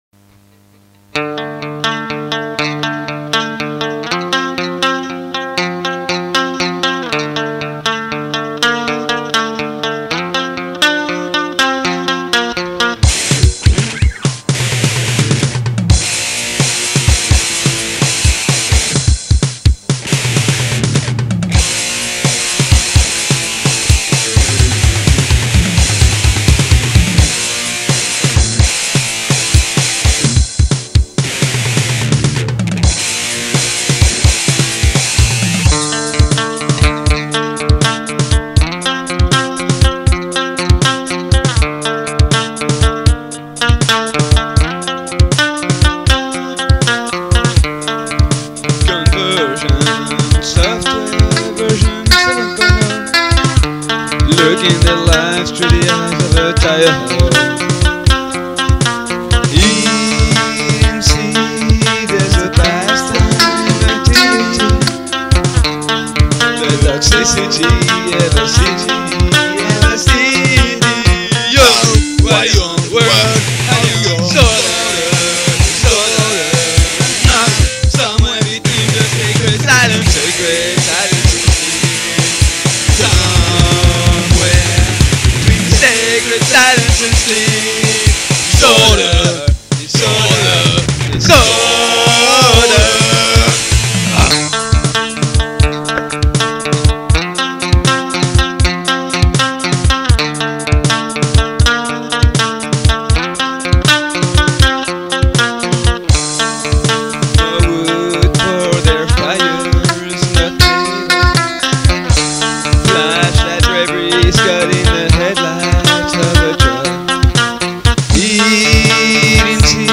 Batterie : GP4